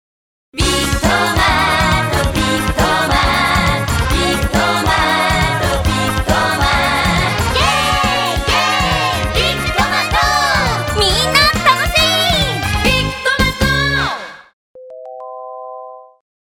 街頭放送CMの専門家
音の広告　街頭放送